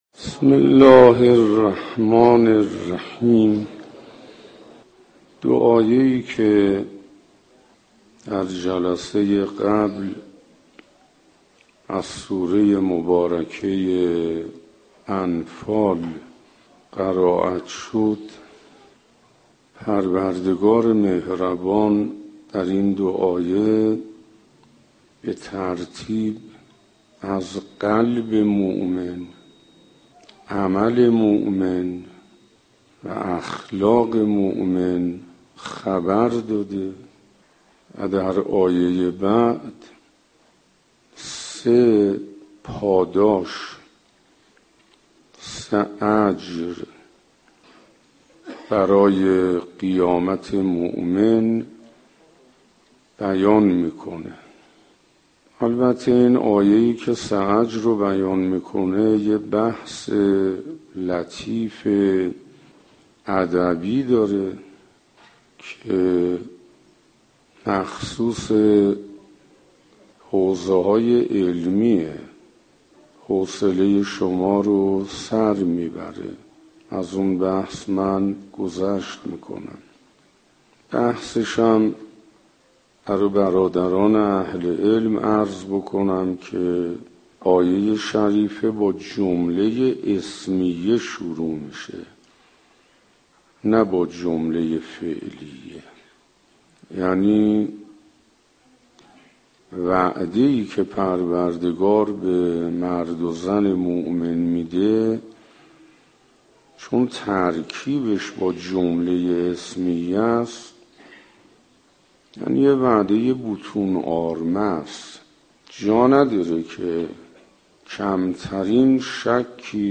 سخنرانی حجت الاسلام انصاریان با موضوع یاد خدا -8 جلسه